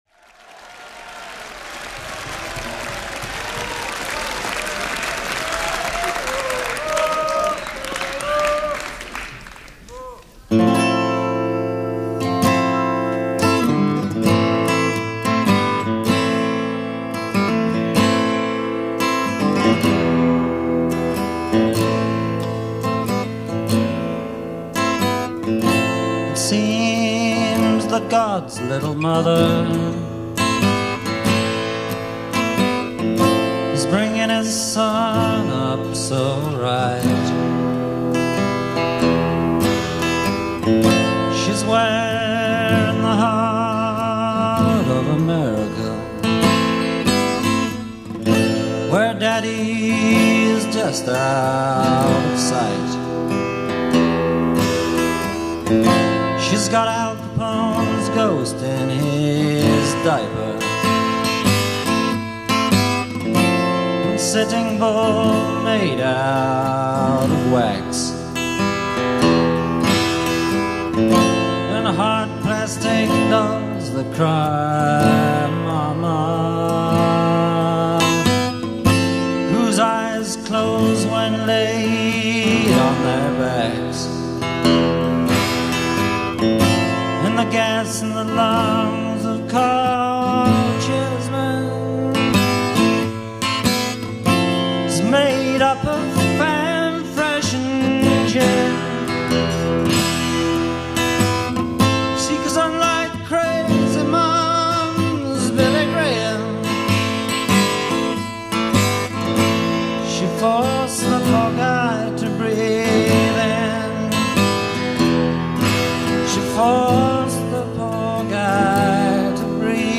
recorded at Golders Green Hippodrome